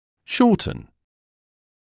[‘ʃɔːtn][‘шоːтн]укорачивать; укорачиваться